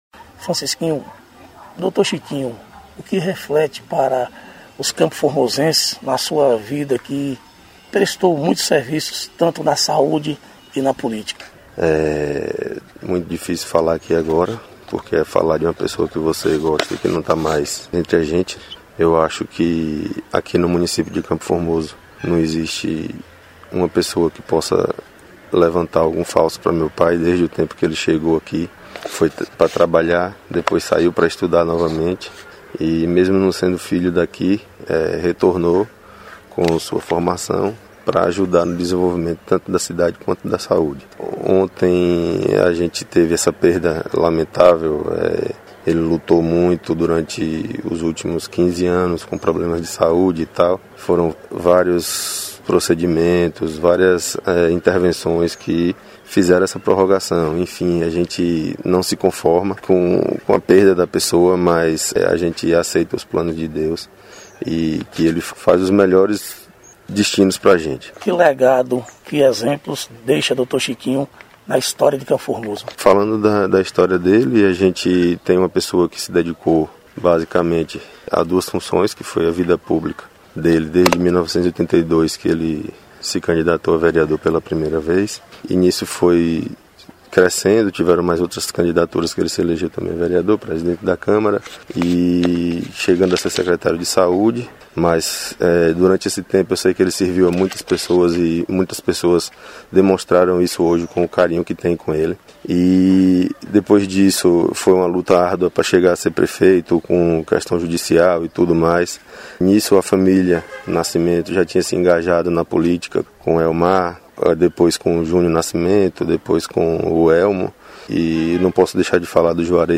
Reportagem: Depoimentos/homenagens